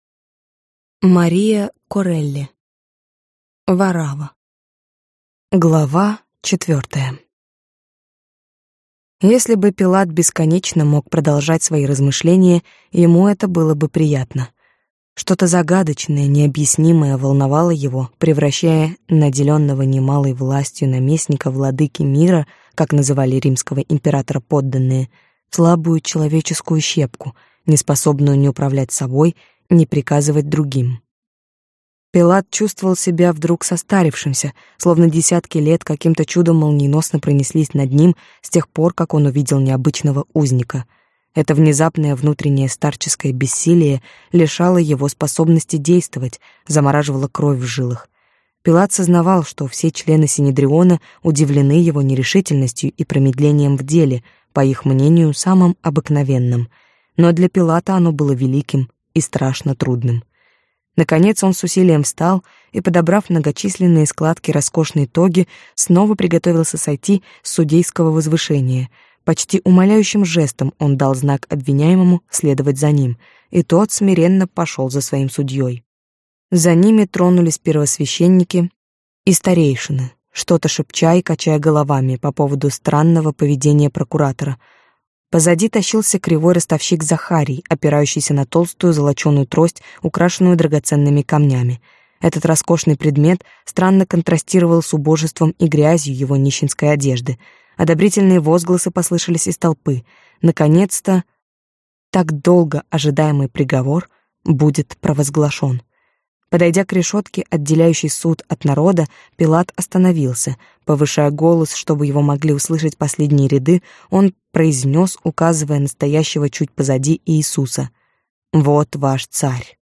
Аудиокнига Варавва | Библиотека аудиокниг